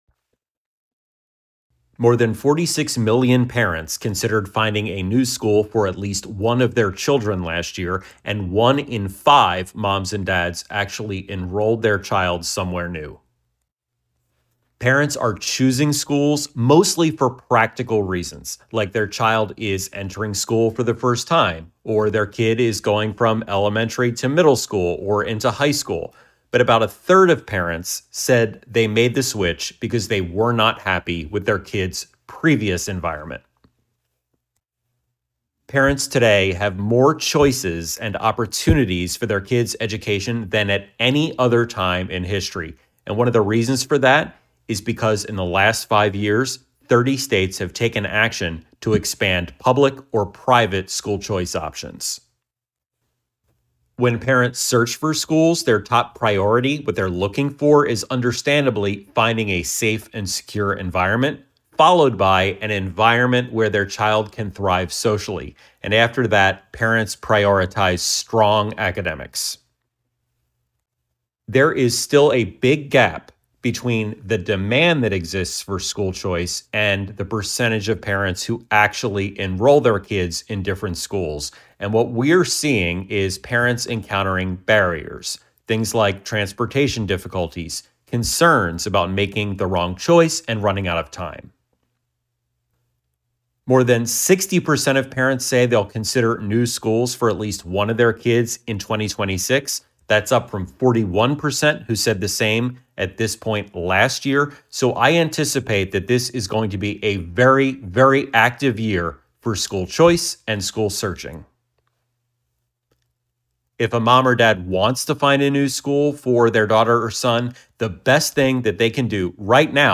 Audio Sound Bites for Radio News